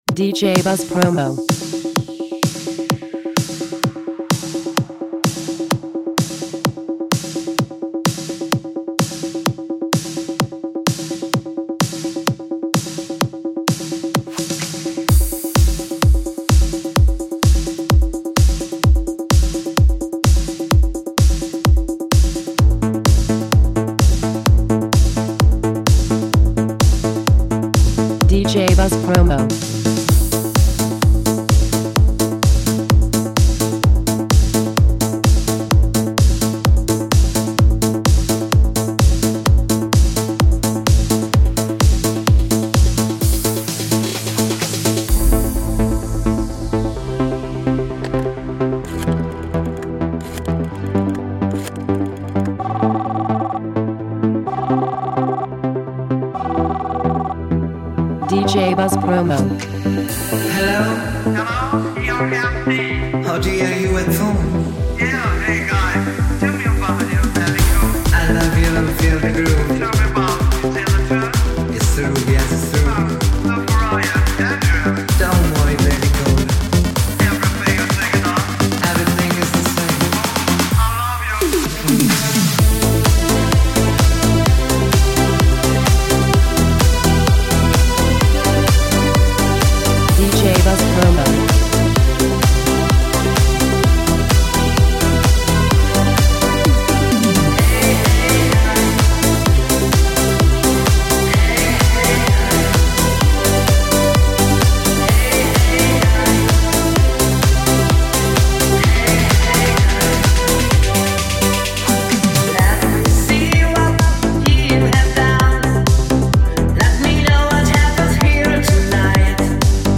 classic Italo Disco sound with contemporary beats
energetic, modern twist